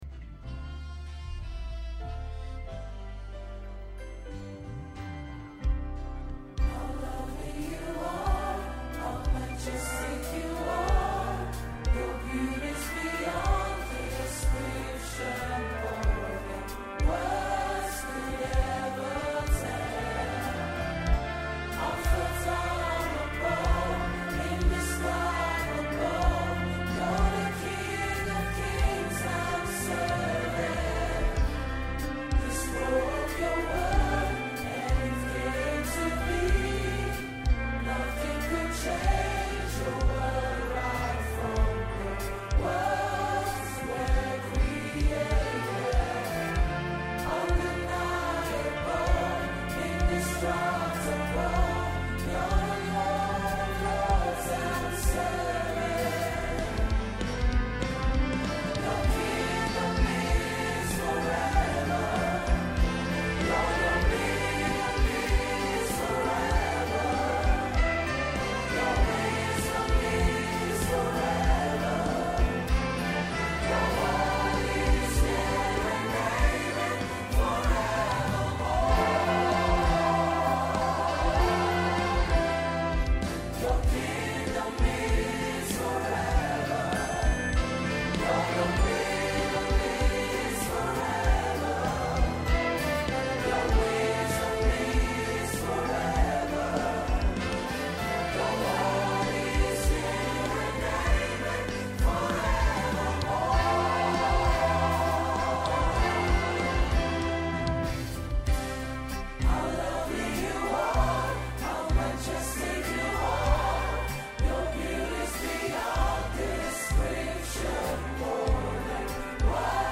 MORE FROM JUNE COMMUNION SERVICE